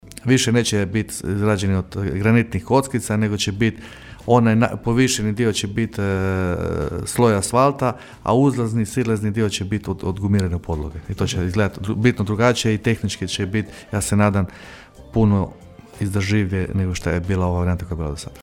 Prvi usporivač, preko puta OTP banke, bit će saniran do Uskrsa, a nakon blagdana krenut će radovi na popravku usporivača koji se nalazi preko puta Müllera. Tijekom sanacije, promijenit će se tehničko rješenje, veli gradonačelnik Labina Valter Glavičić: (